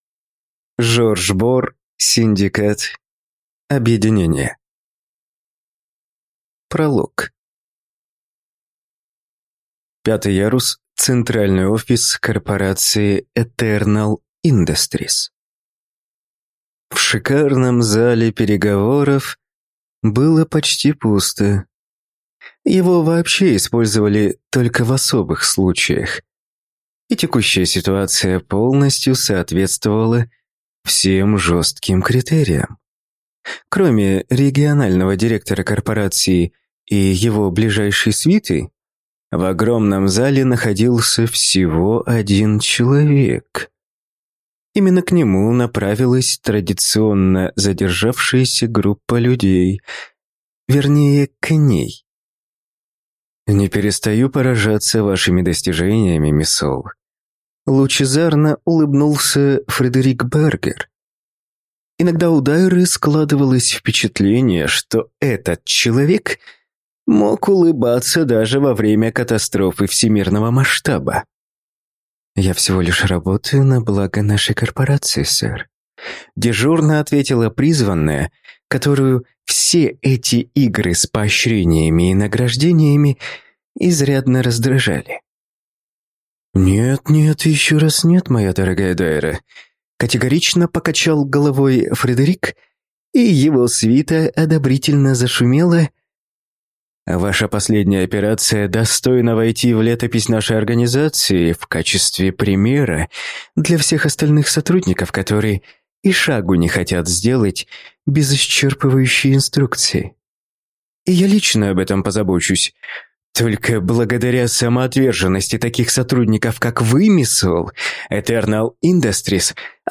Аудиокнига Синдикат. Объединение | Библиотека аудиокниг